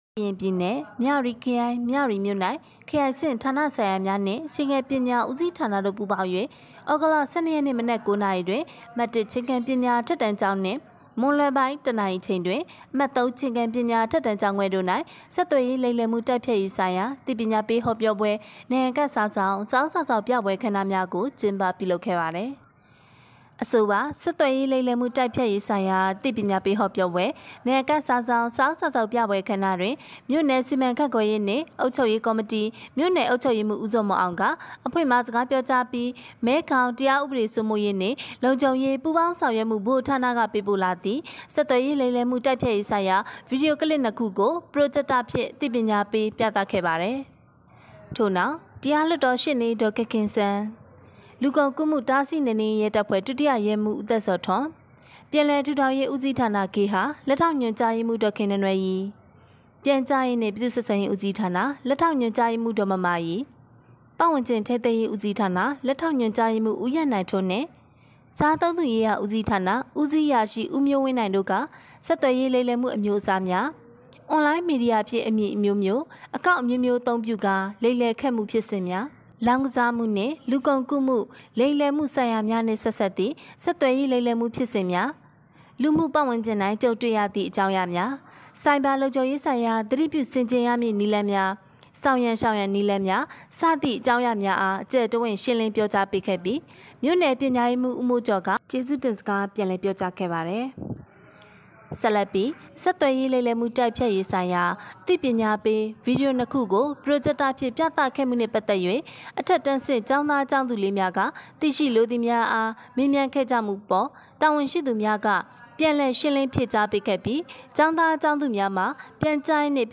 ဆက်သွယ်ရေးလိမ်လည်မှုတိုက်ဖျက်ရေးဆိုင်ရာအသိပညာပေးဟောပြောပွဲ၊နံရံကပ်စာစောင်၊စာအုပ်စာစောင်ပြပွဲ အခမ်းအနားကျင်းပ